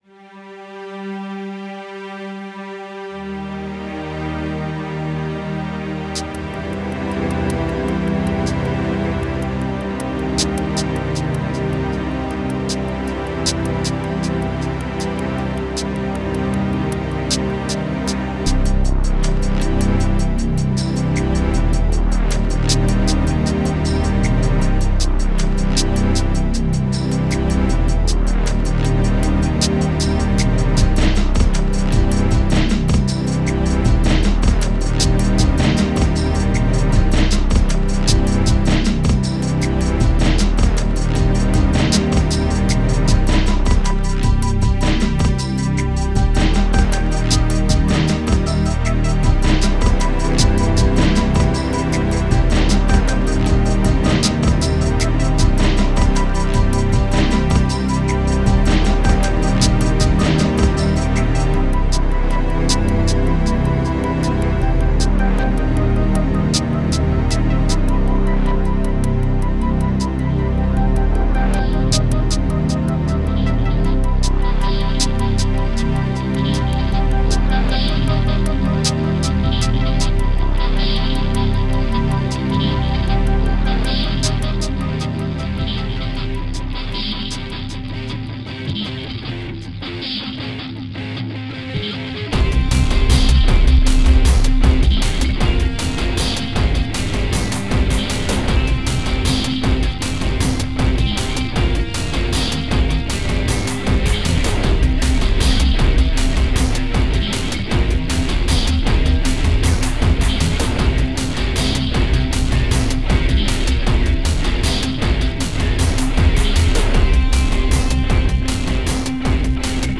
The track I produced portrays the emotions of drifting to sleep, followed by the comencement of an intense dream.